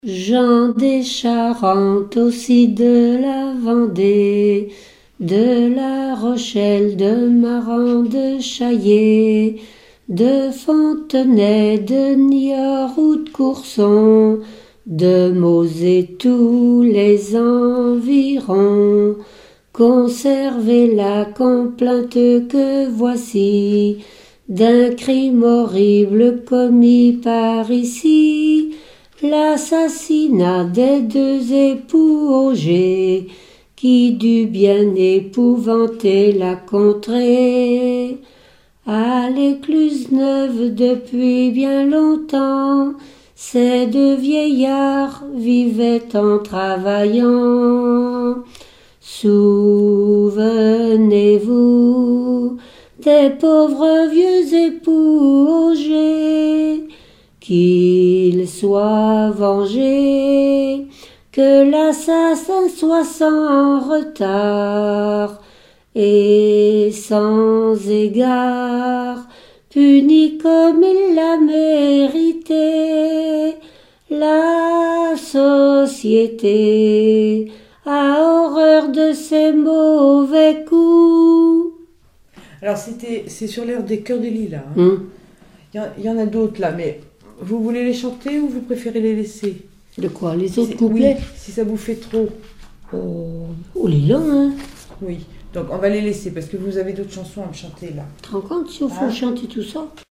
répetoire de chansons traditionnelles et populaires
Pièce musicale inédite